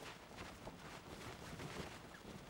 cloth_sail12.R.wav